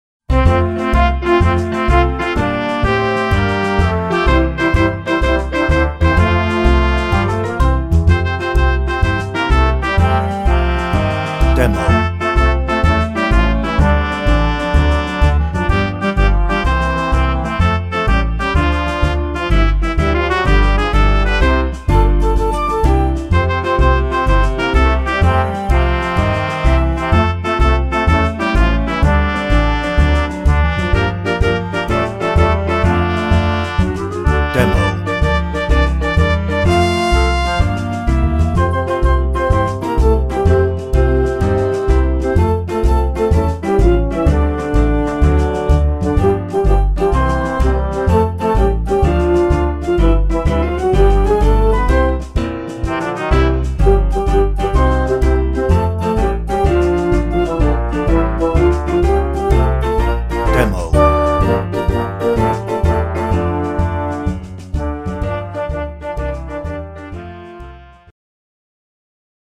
No ref vocal
Instrumental